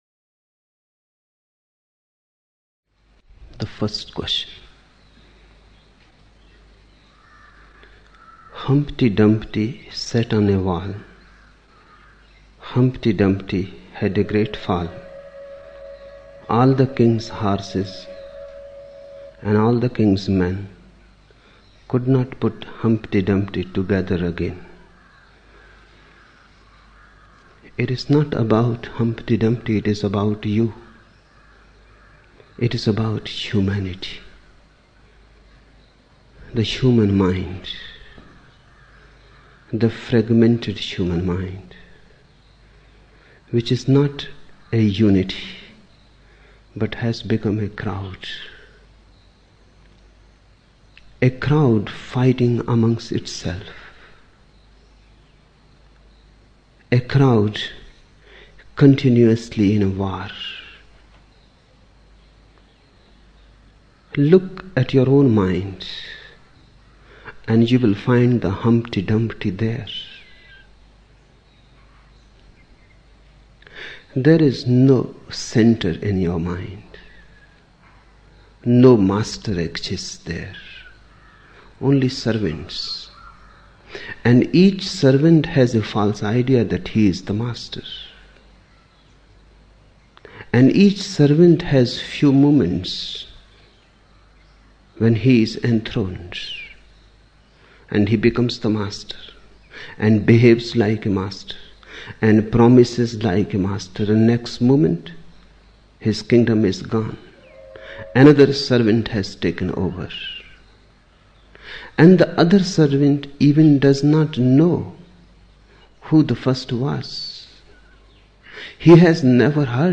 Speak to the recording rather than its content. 9 November 1975 morning in Buddha Hall, Poona, India